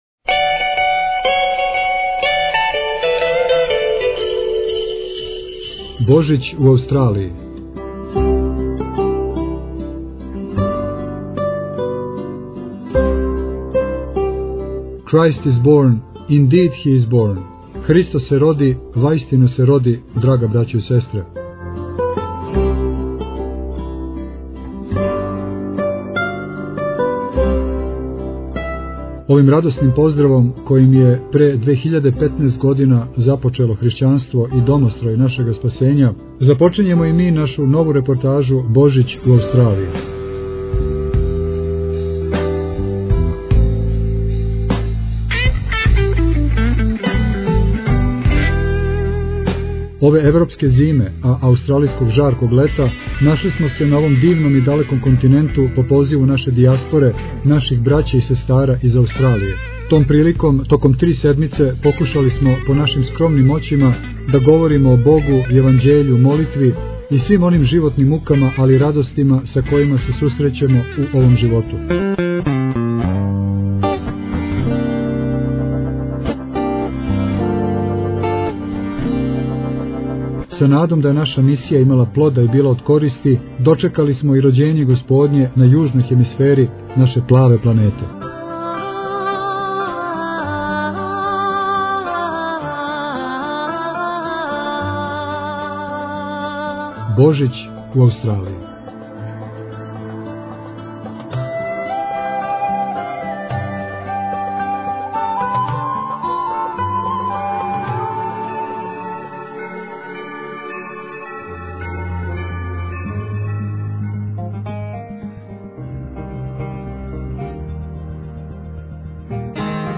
Овим радосним поздравом, којим је пре 2015. година започело Хришћанство и домострој нашега спасења, започињемо и ми нашу нову репортажу. Преузмите аудио датотеку 763 преузимања 184 слушања Прочитајте више Божић у Аустралији VI Tagged: Божић у Аустралији 73:27 минута (12.61 МБ) Christ is born - indeed hi is born!